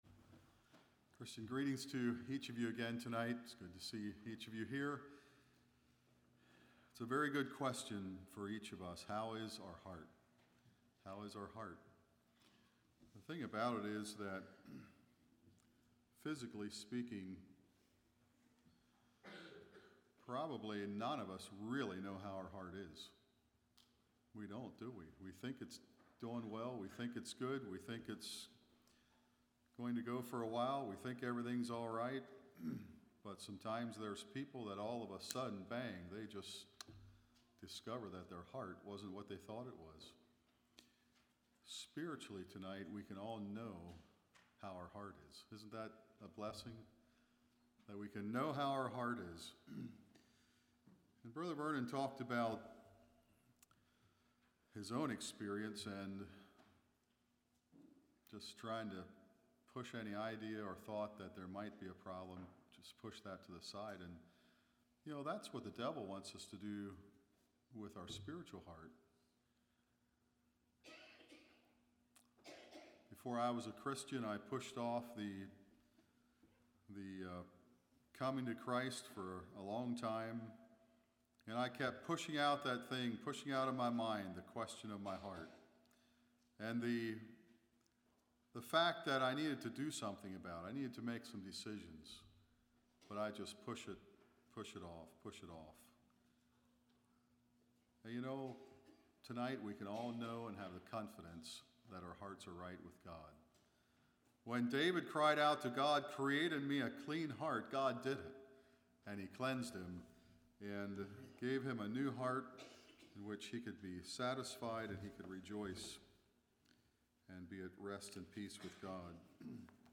Play Now Download to Device Youth Developing Moral Integrity Congregation: Calvary Speaker